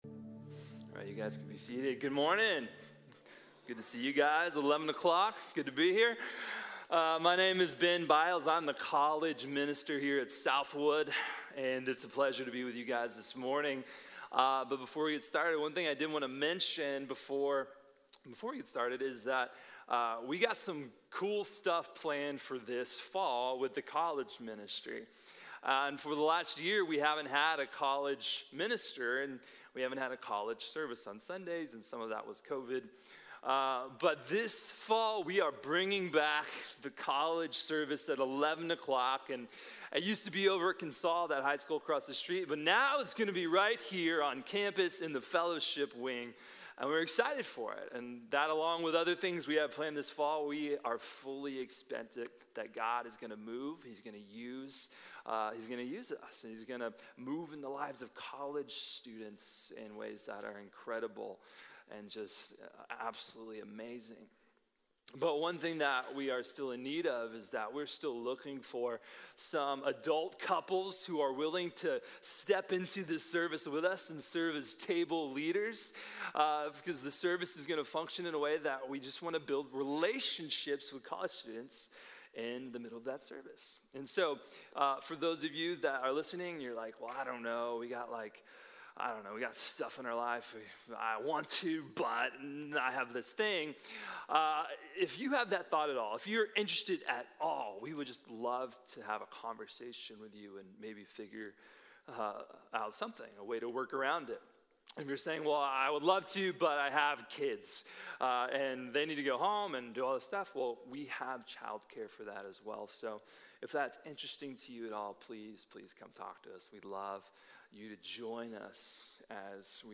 Job’s Suffering & God’s Justice | Sermon | Grace Bible Church